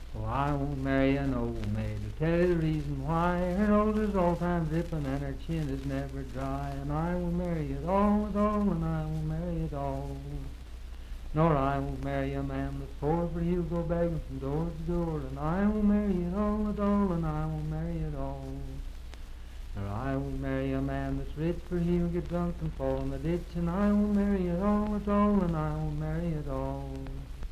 Unaccompanied vocal music
Marriage and Marital Relations, Dance, Game, and Party Songs
Voice (sung)
Pocahontas County (W. Va.), Marlinton (W. Va.)